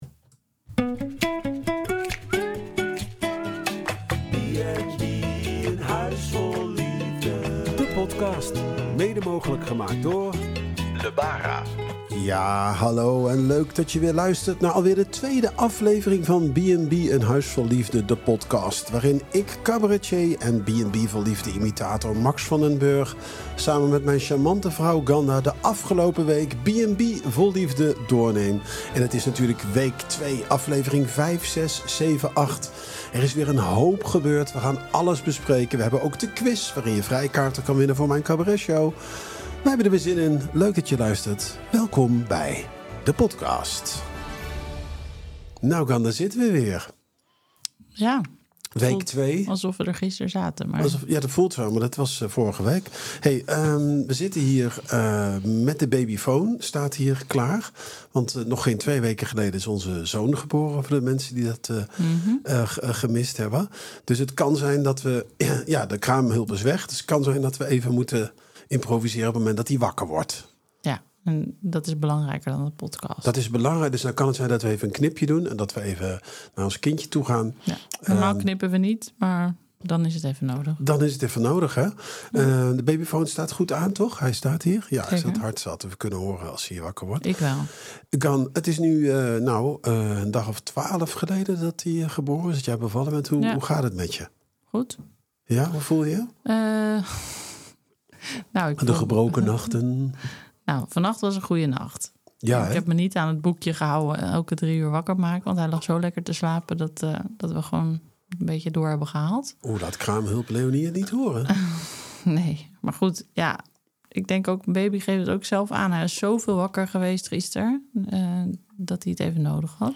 gaan weer een uur in gesprek